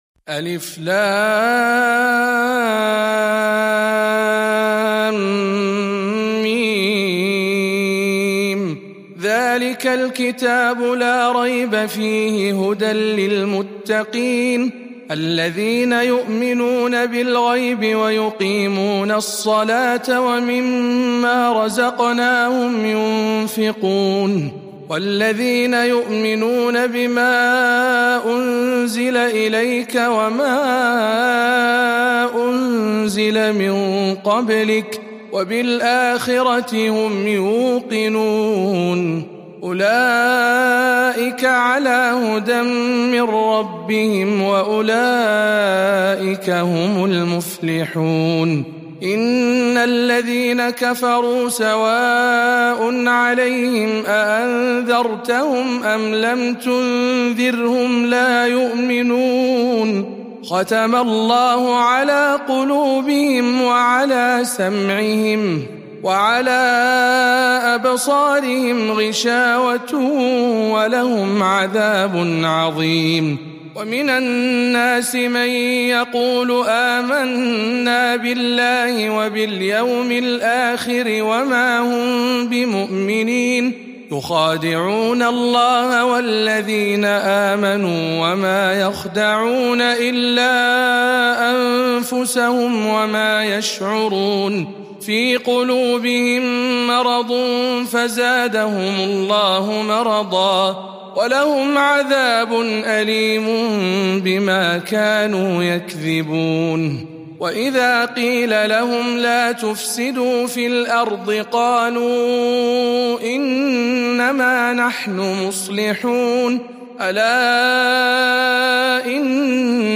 سورة البقرة برواية شعبة عن عاصم